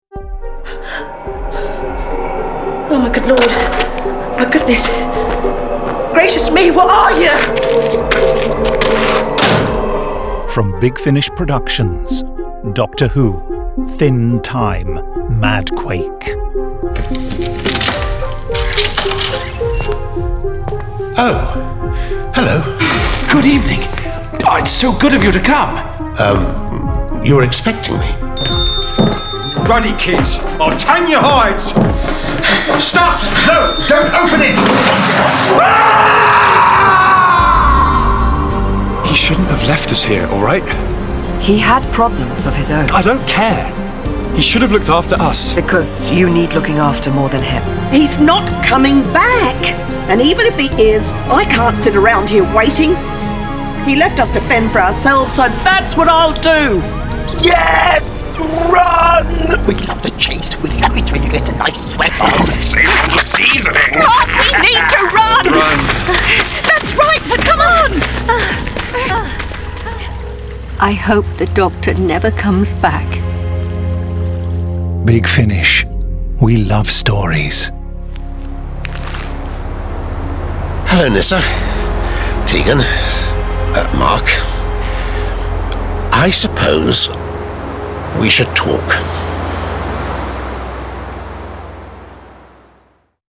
Starring Peter Davison Sarah Sutton